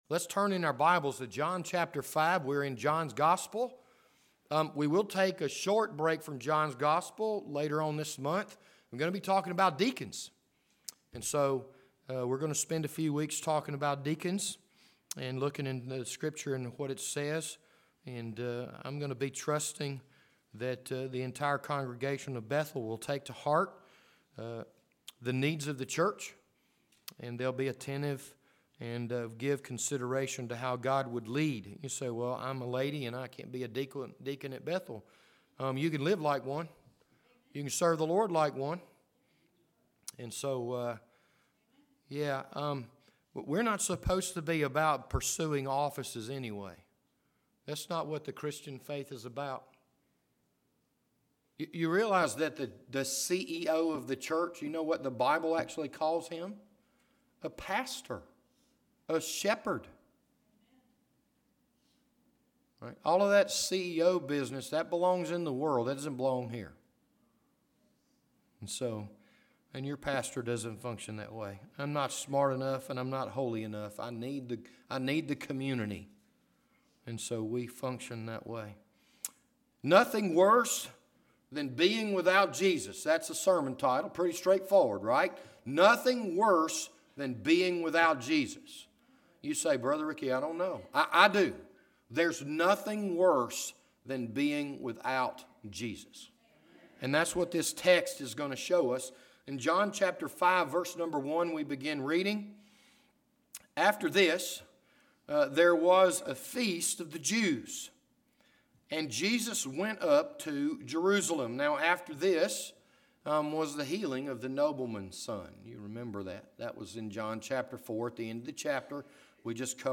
This Sunday morning sermon was recorded on July 14th, 2019.